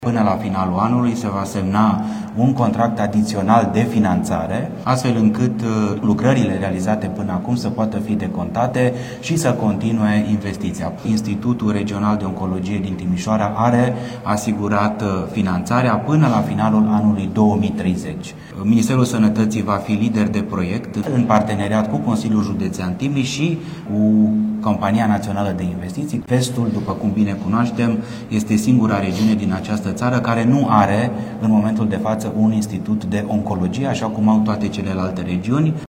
Ministrul Sănătății, Alexandru Rogobete: „Vestul, după cum bine cunoaștem, este singura regiune din această țară care nu are un Institut de Oncologie”